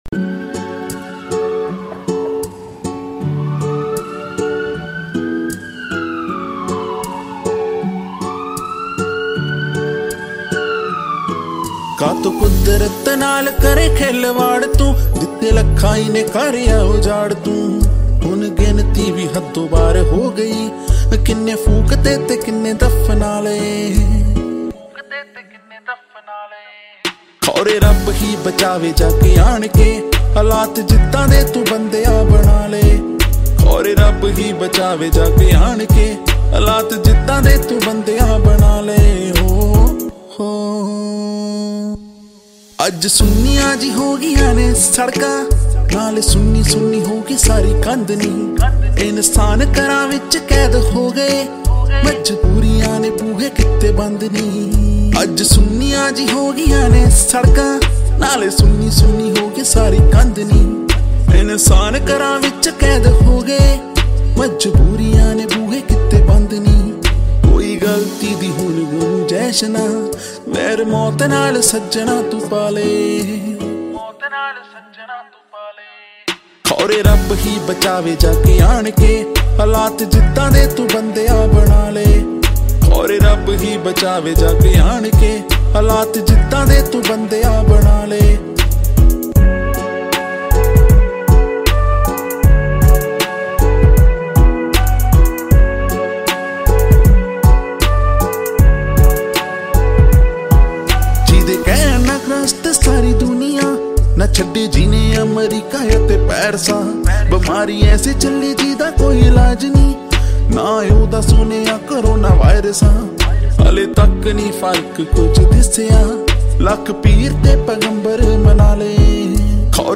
Latest Punjabi Songs